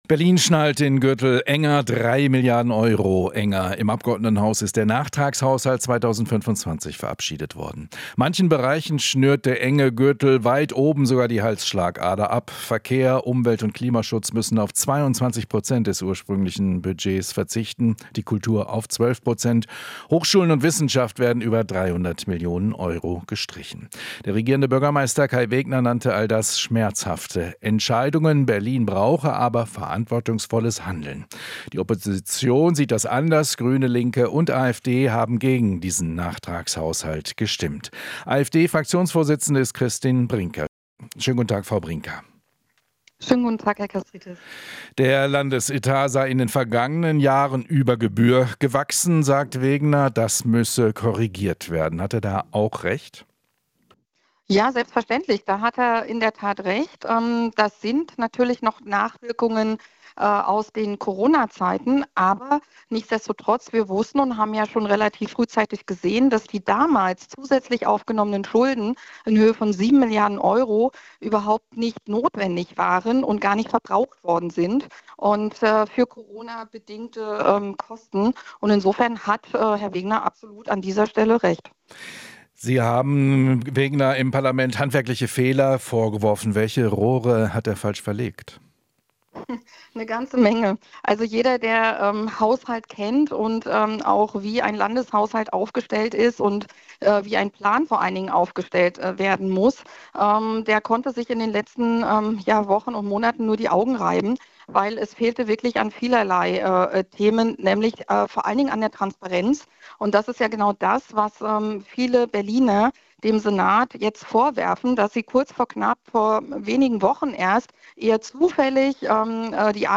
Interview - Brinker (AfD) übt Kritik an Nachtragshaushalt